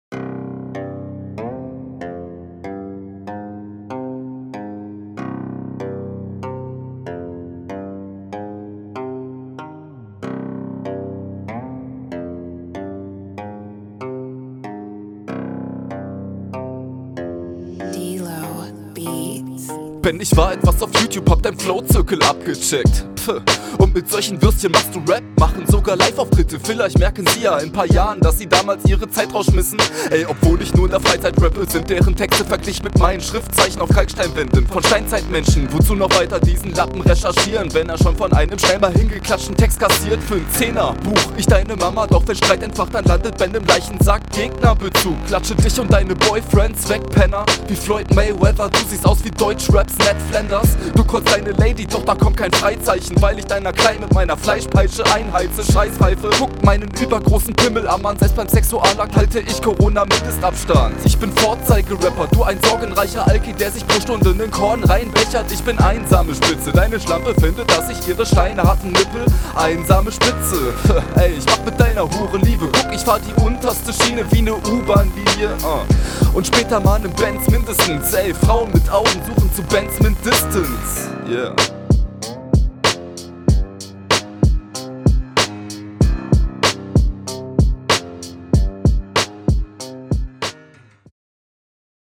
Flow ist sehr vorhersehbar und Reime klingen ausgelutscht. also …